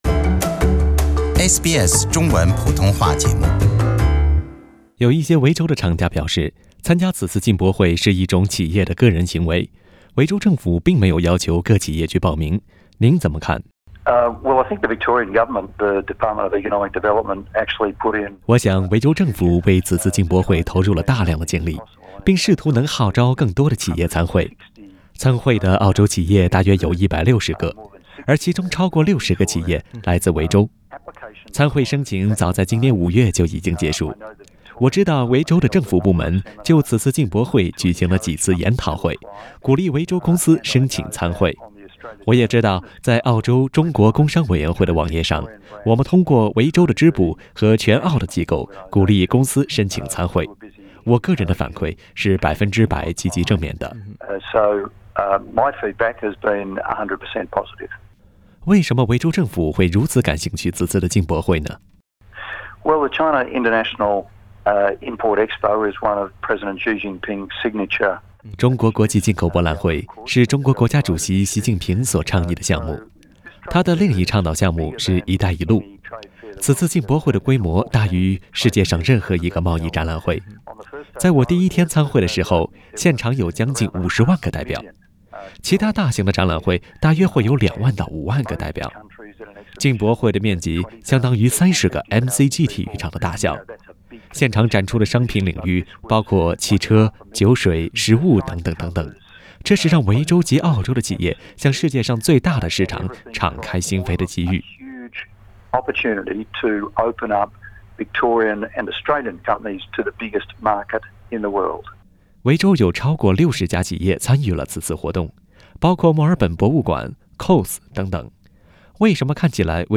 以下是本台记者对John Brumby先生的采访。